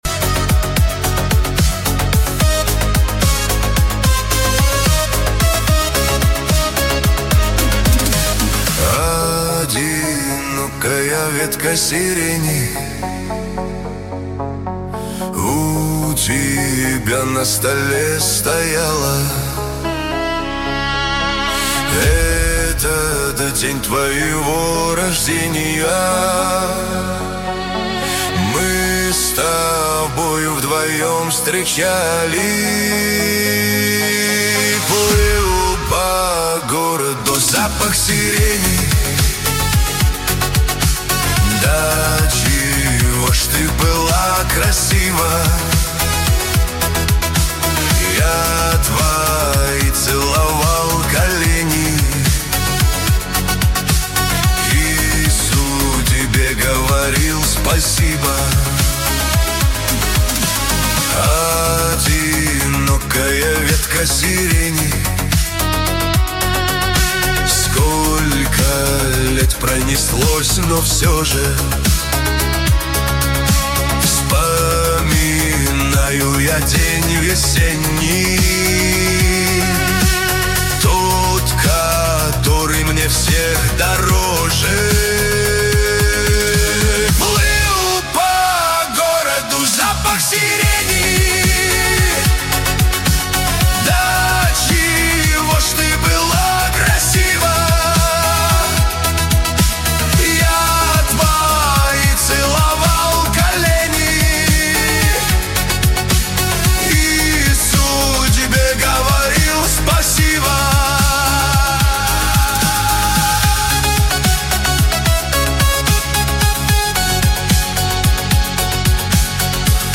Русские треки, 2026, Русские поп песни